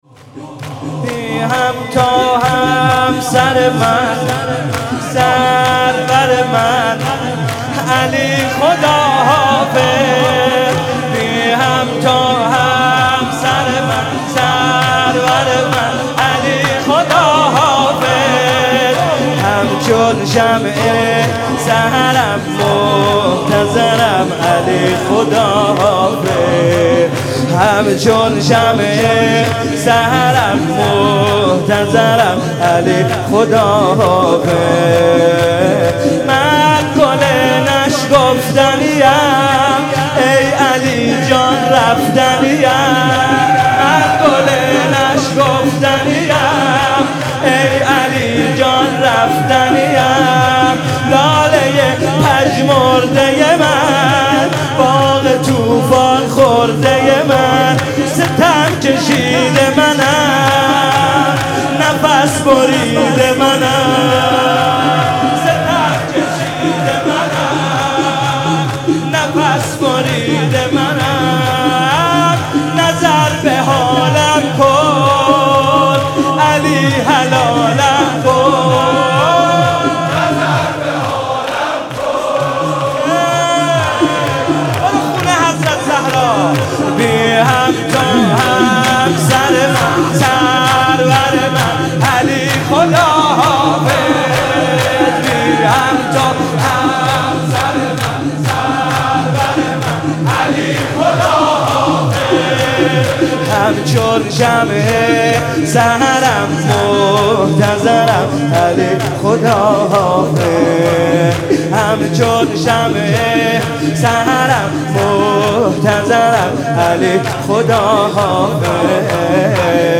شور - بی همتا همسر علی